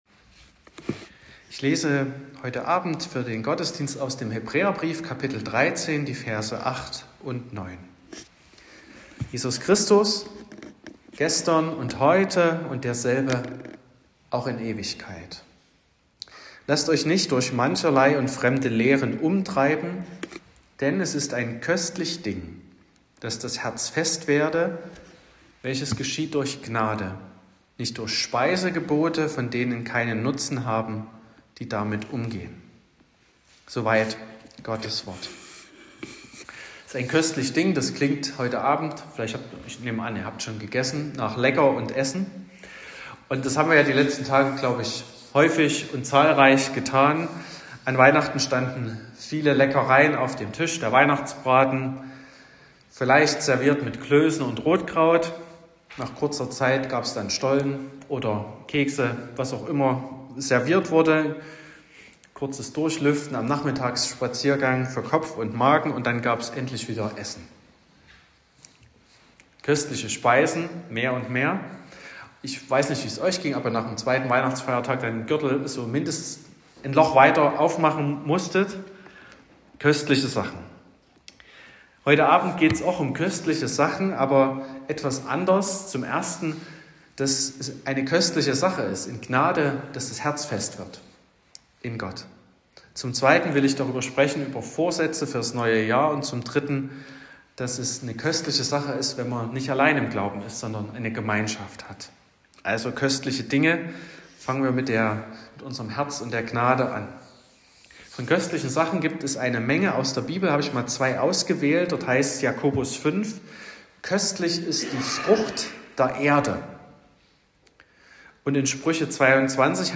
Predigt und Aufzeichnungen